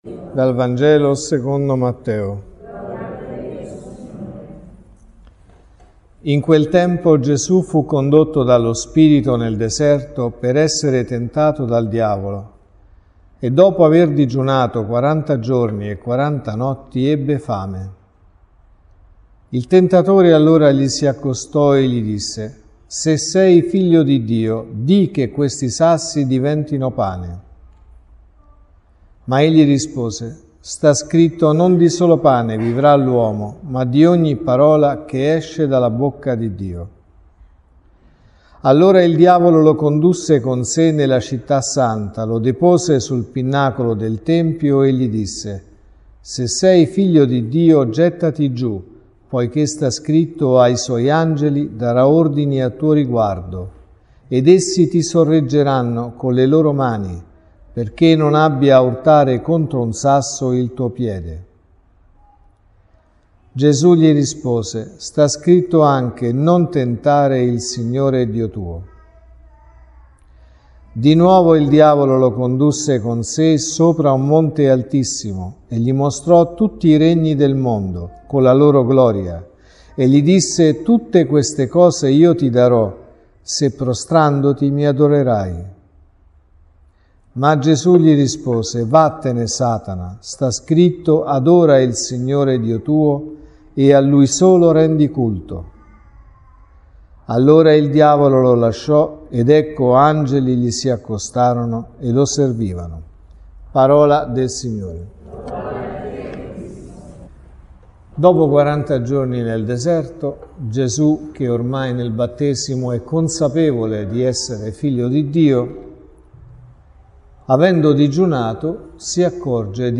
Gesù digiuna per quaranta giorni nel deserto ed è tentato.(Messa del mattino e della sera)